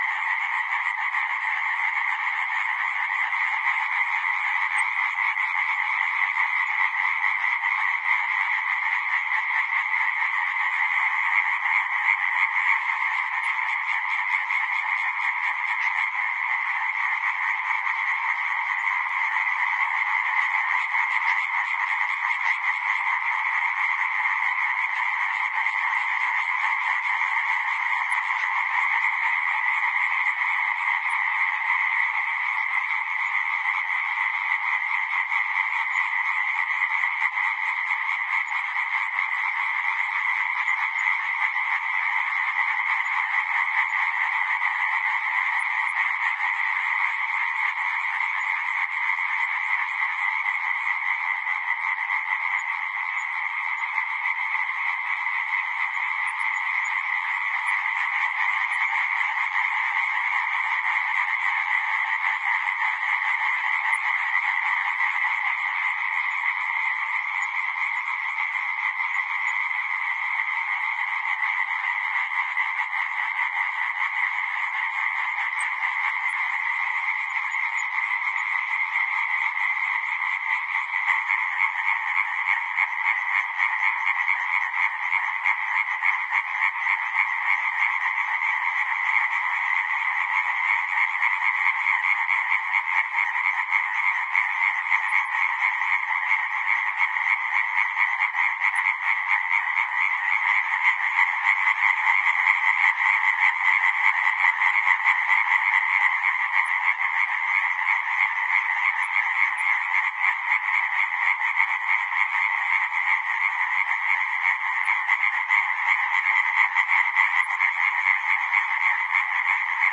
青蛙 " 青蛙
描述：青蛙在半夜呱呱叫。远处还有一些夜莺在唱歌。NT4BMA2R09HR.
标签： 环境 气氛 鸟鸣声 叫声 池塘 池塘里生活 唱歌 距离遥远 自然记录 晚上 被安排到 呱呱叫 青蛙 青蛙
声道立体声